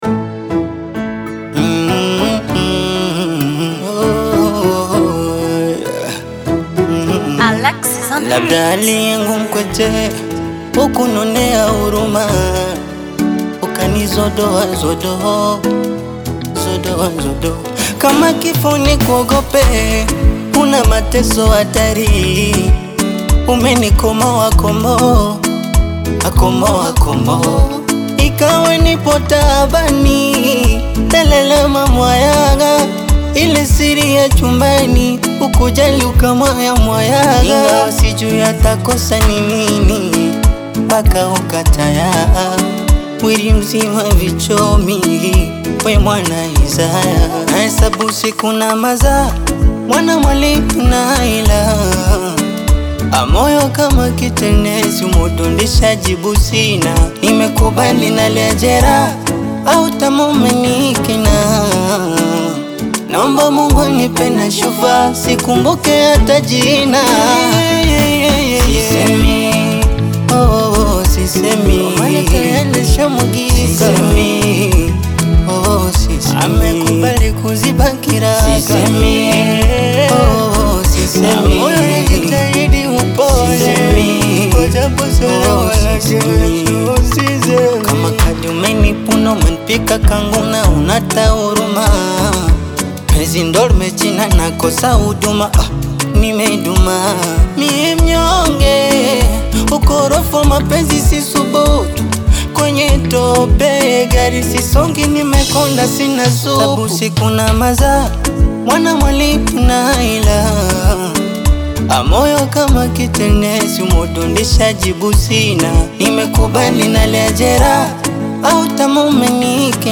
Bongo Flava / Afropop / Afrobeats
heartbreaking love song